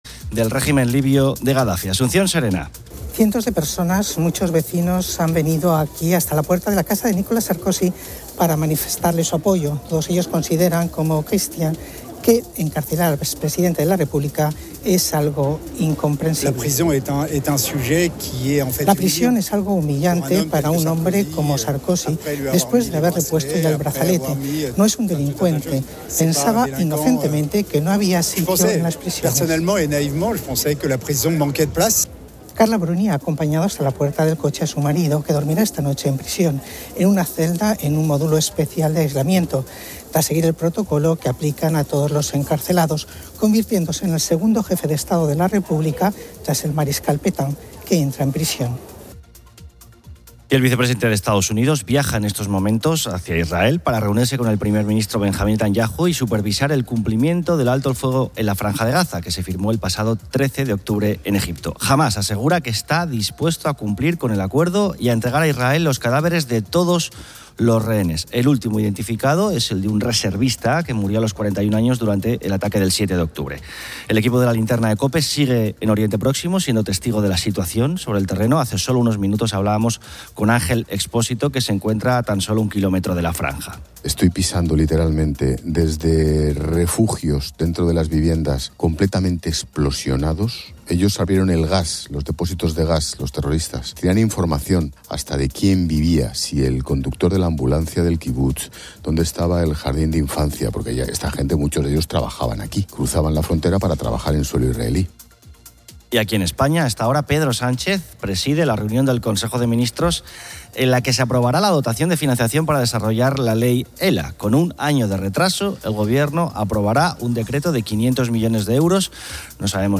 El programa continúa con "La Hora de los Fósforos", donde los oyentes y presentadores comparten chistes y debaten temas variados como la percepción de olores o las dificultades para obtener el carnet de conducir. También se aborda el Día de Regreso al Futuro y la relevancia de los chistes en la vida cotidiana.